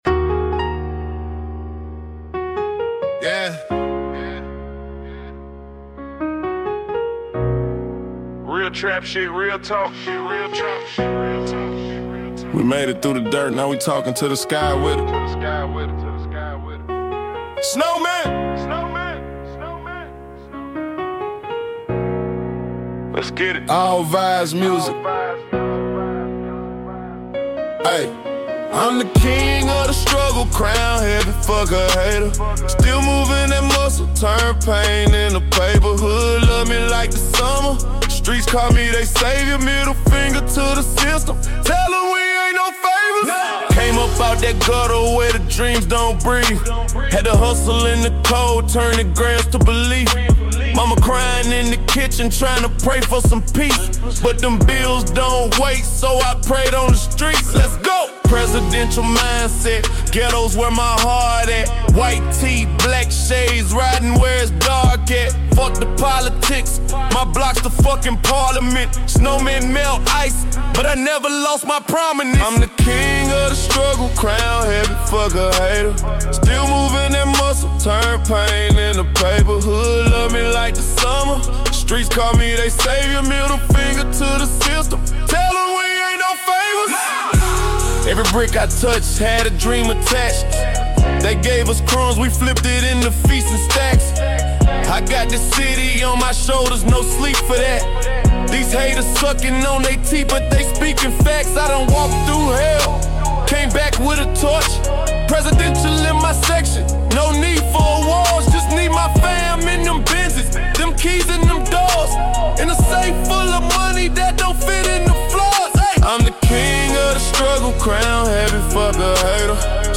bass-heavy street anthem
🏆 🎶 This is REAL trap motivation.